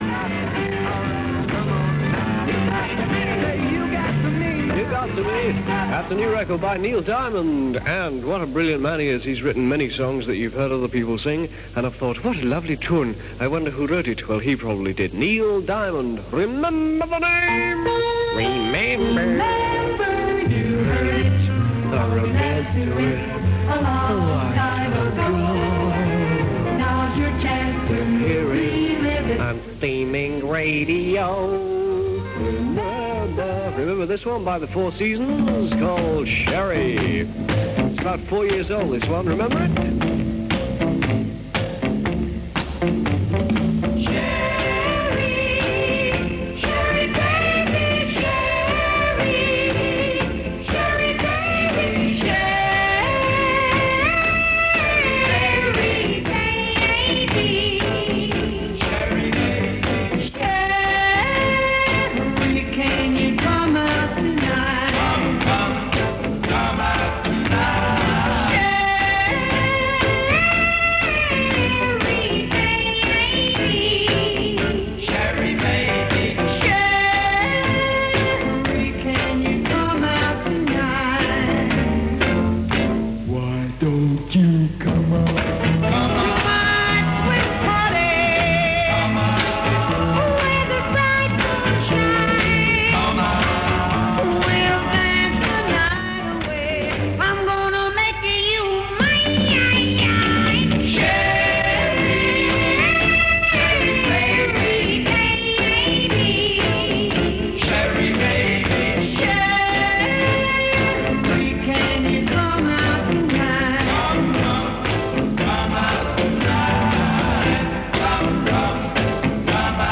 It’s 30 minutes of a ‘radio DJ’ who obviously has no understanding of the rules of radio.
Never interrupt a song whilst it is playing by suddenly joining in with it. Never stumble and put on a silly voice whilst delivering a dry read of an advert.
kenny-everett-sat-4th-feb-1967-on-radio-london.m4a